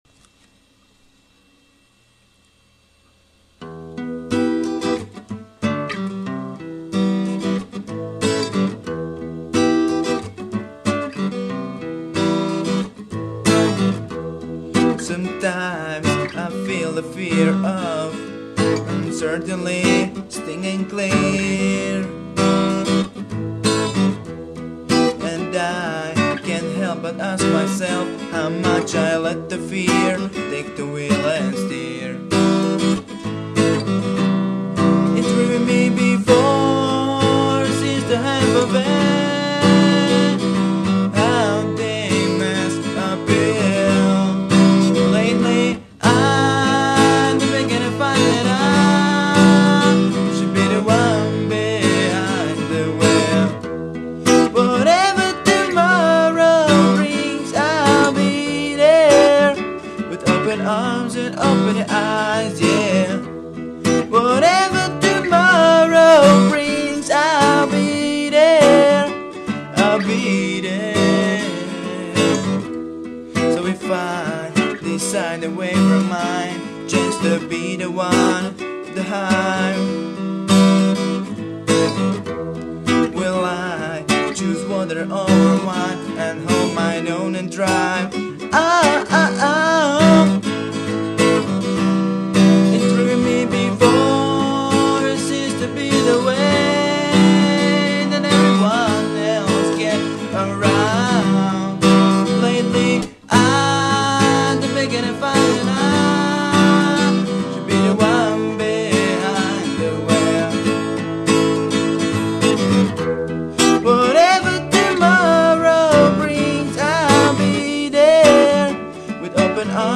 Styl: Acoustic